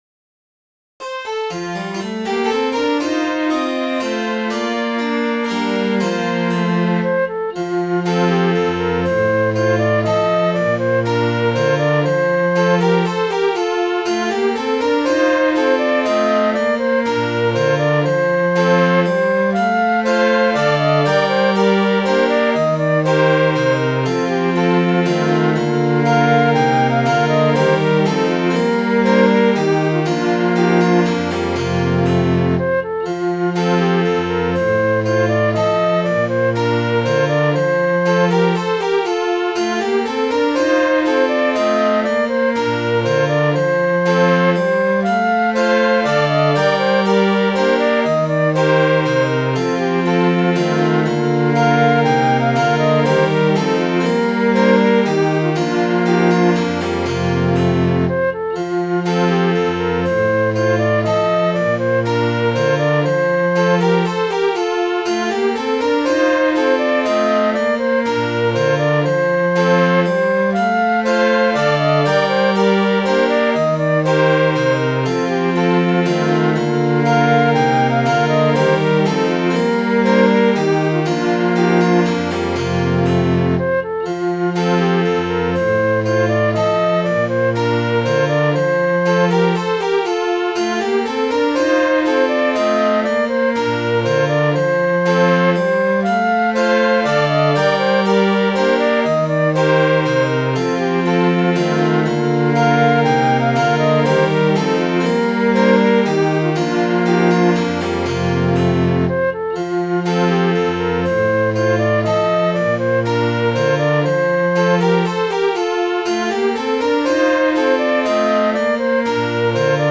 Midi File, Lyrics and Information to Poor Sally Sits A-Weeping